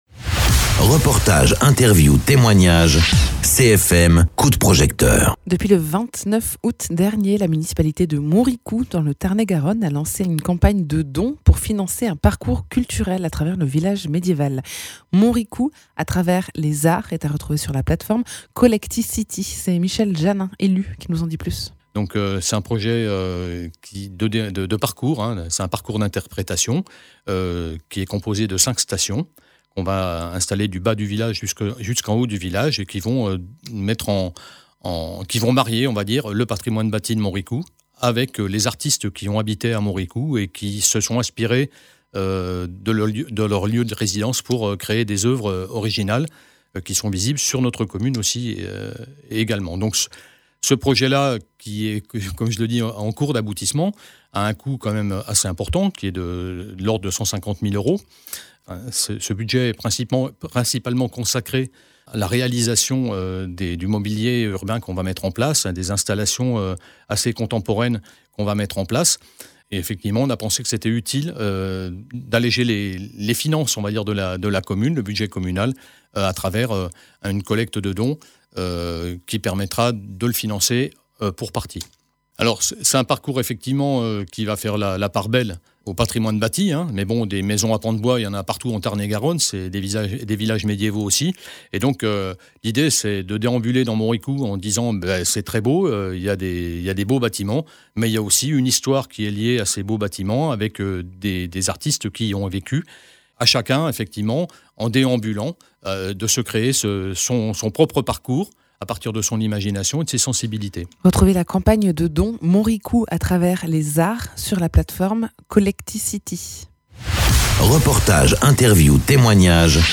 Interviews
Invité(s) : Michel Jannin, élu à Montricoux